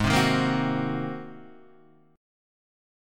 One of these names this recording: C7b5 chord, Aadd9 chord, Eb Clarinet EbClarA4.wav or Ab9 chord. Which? Ab9 chord